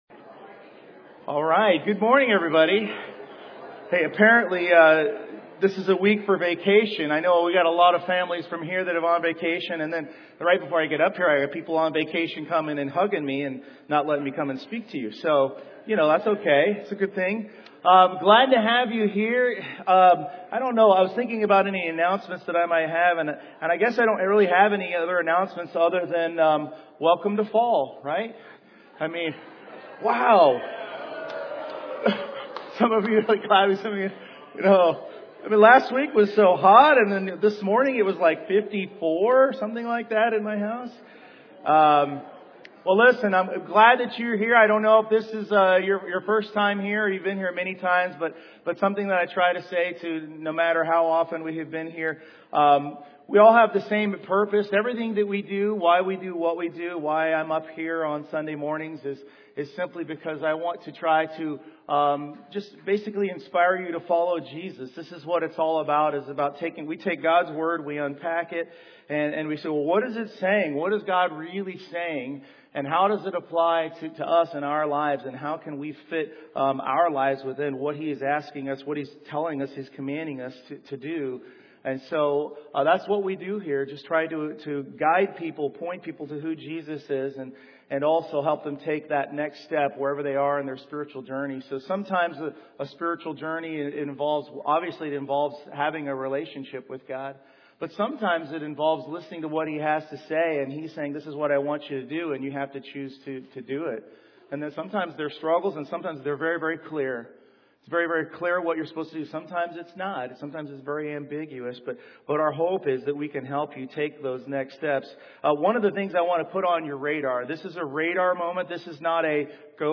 Series: 2025 Sermons